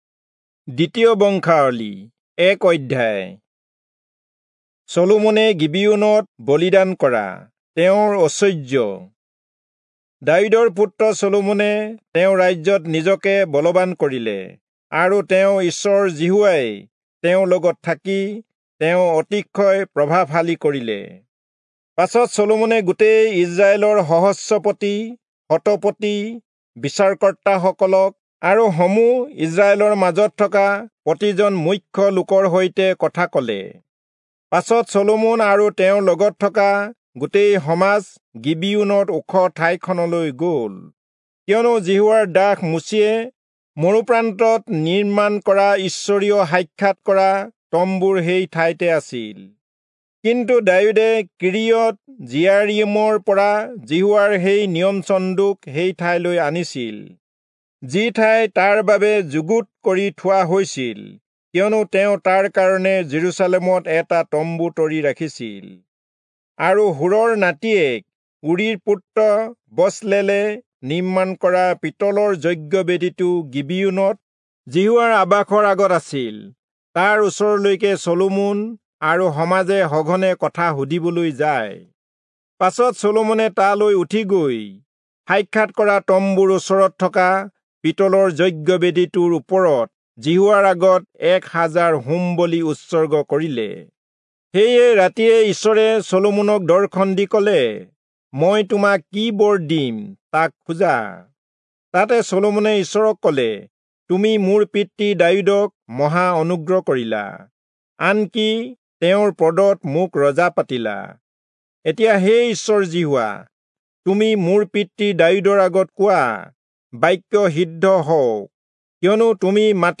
Assamese Audio Bible - 2-Chronicles 8 in Wlc bible version